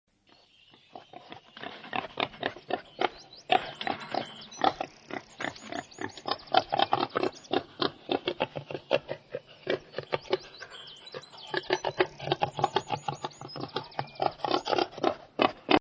Wildschwein
Das typische Geräusch der Wildschweine ist ihr Grunzen. Sie leben in so genannten Rotten mit 5-10 Mitgliedern zusammen.
wildschwein.mp3